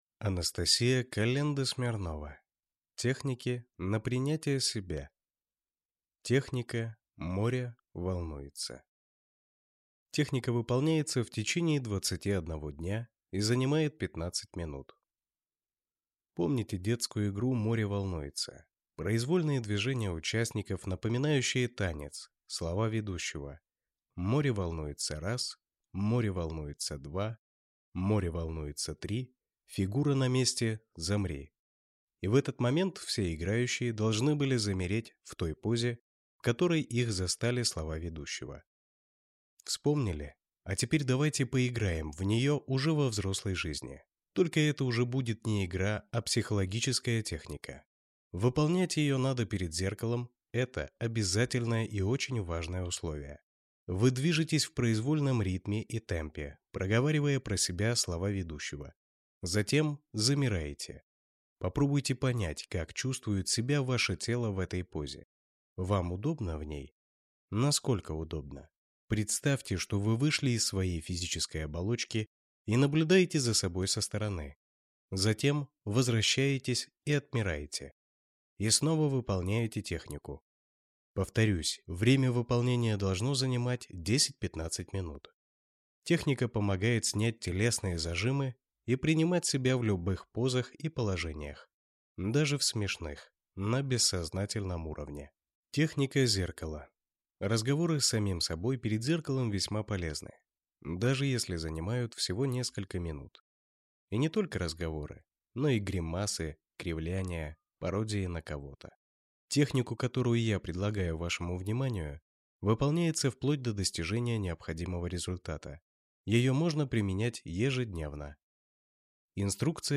Аудиокнига Техники на принятие себя | Библиотека аудиокниг